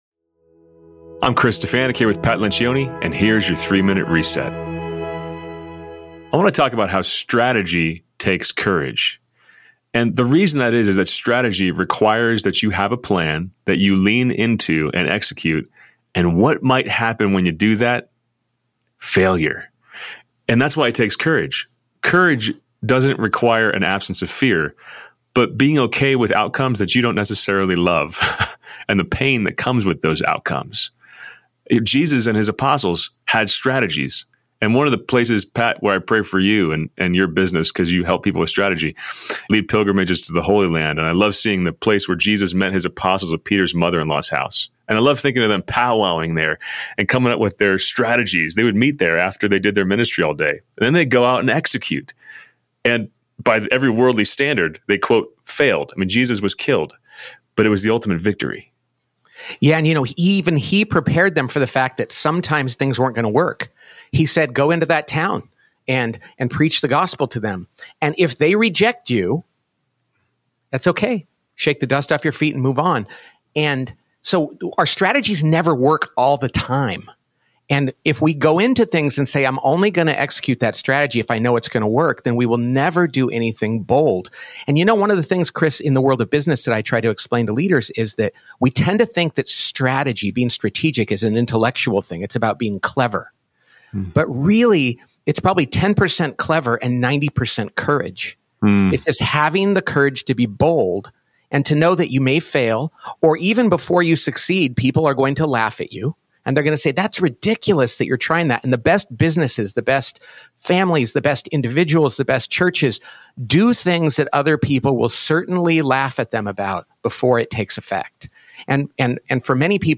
a daily reflection for Christians in the workplace.